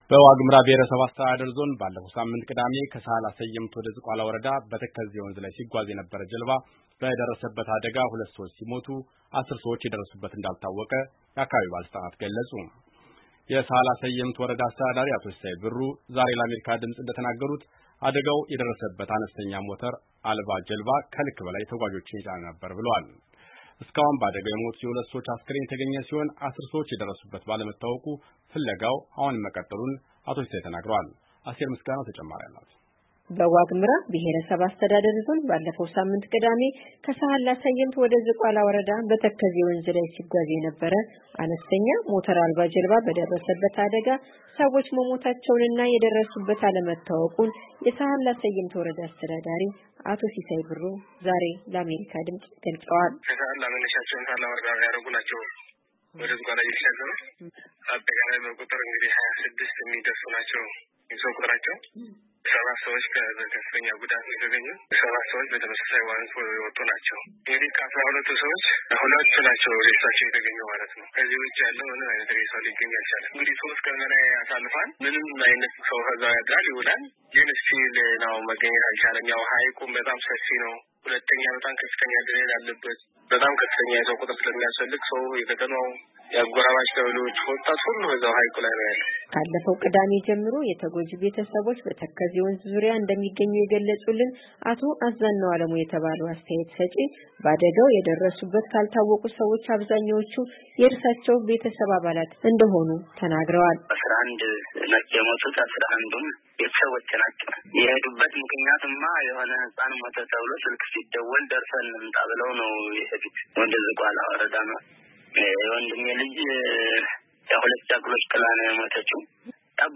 የሰሀላ ሰየምት ወረዳ አስተዳዳሪ አቶ ሲሳይ ብሩ፣ ዛሬ ለአሜሪካ ድምፅ እንደተናገሩት፣ አደጋው የደረሰበት አነስተኛ ሞተር አልባ ጀልባ “ከልክ በላይ ተጓዦችን የጫነ ነበር፤” ብለዋል፡፡